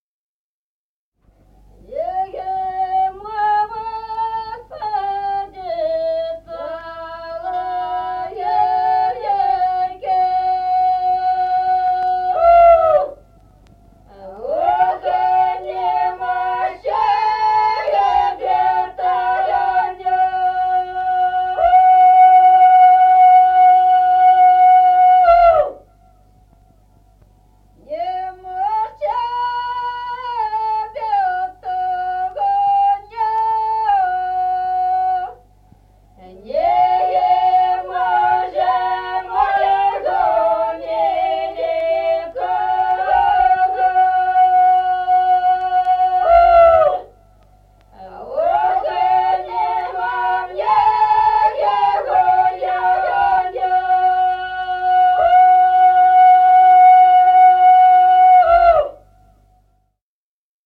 Песни села Остроглядово. Нема в саде соловейки (жнивная).